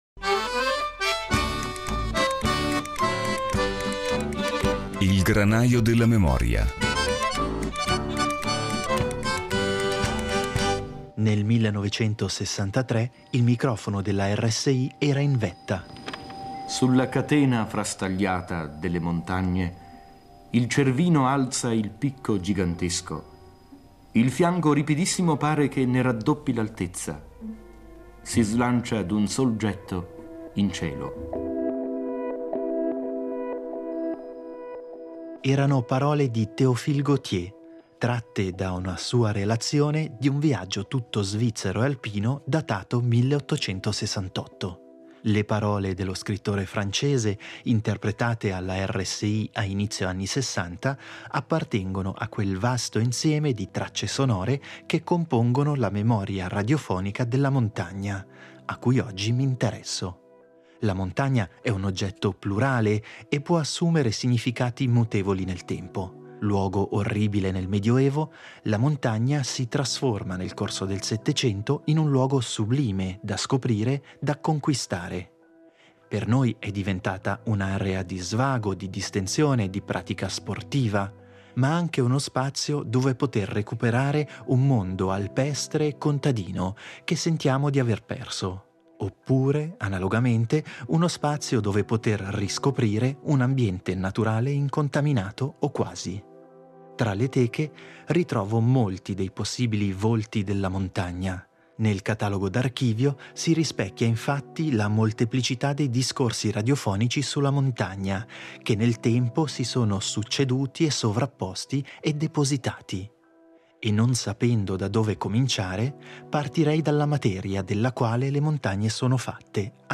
Per noi è diventata un’area di svago, di distensione e un ambiente dove poter ritrovare un mondo, alpestre e contadino, che sentiamo di aver perso. Percorrendo le Teche della RSI, il "Granaio della memoria" ritrova questa settimana le memorie sonore dei molti volti della montagna.